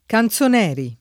[ kan Z on $ ri ]